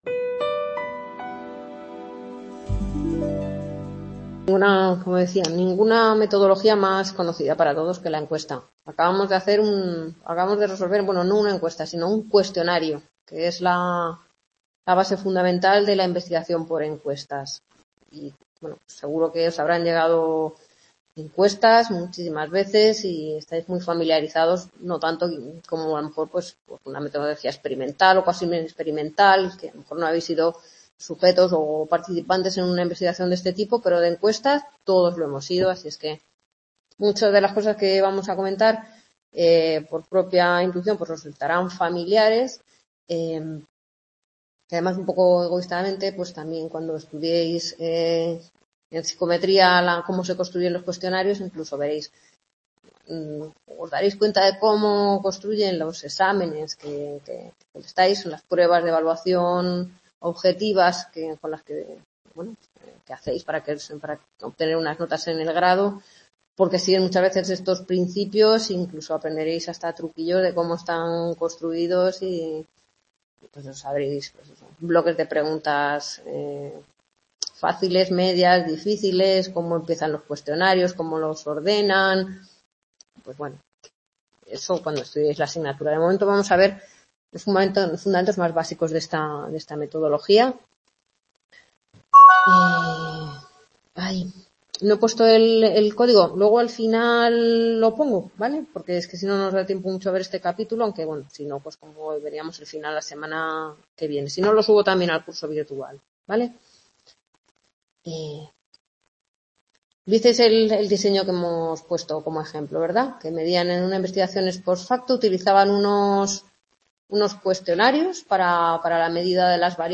Grabación de la novena tutoría (segunda parte) de la asignatura Fundamentos de investigación del Grado en Psicología impartida en el C.A. Rivas (UNED, Madrid). Corresponde a la explicación del capítulo 8, Encuesta, hasta el punto 8.4.2.1.1 Tipos de preguntas.